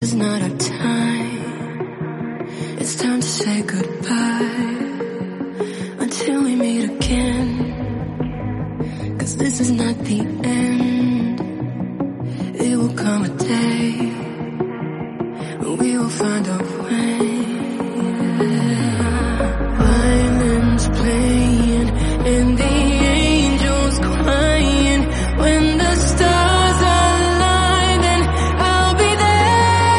поп
нарастающие